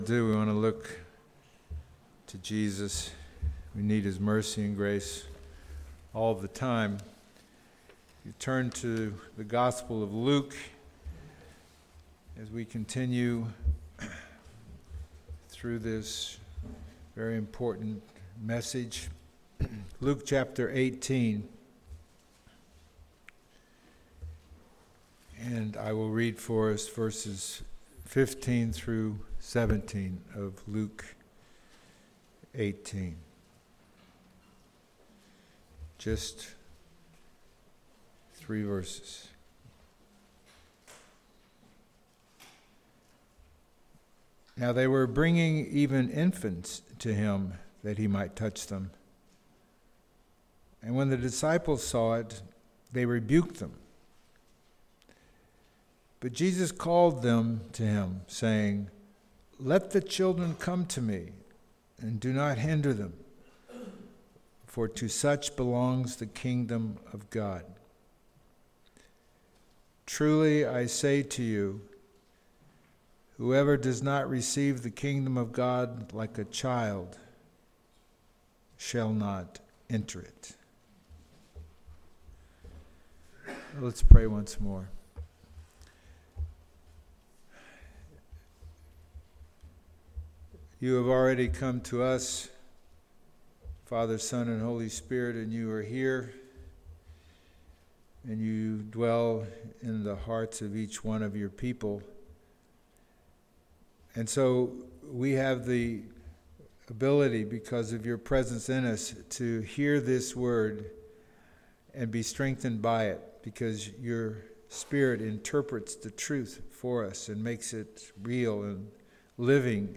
Sunday Morning | The Bronx Household of Faith